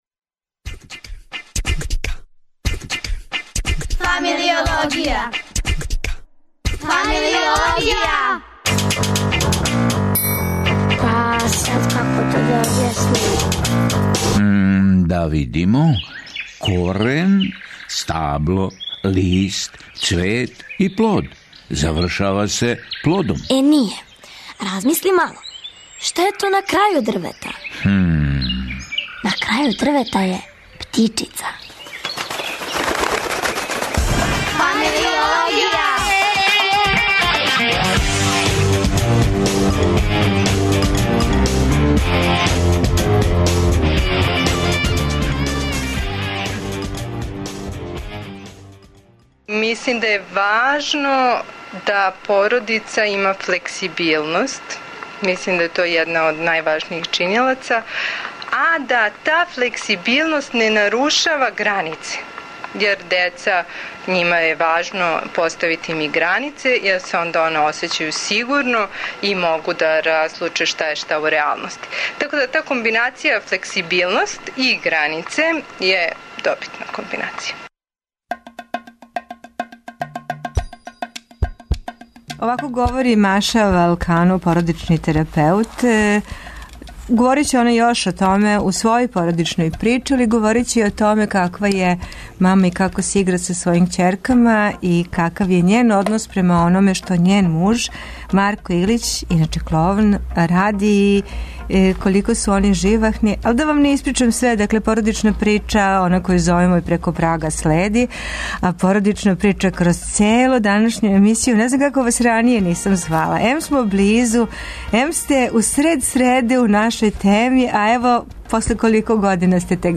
Шест немогућих ствари, пет разговора - о пролећу, заљубљивању у науку, путовању по интернету, о плавим шумама и о биографији воде. И четири музичке нумере чине овај корак ка науци.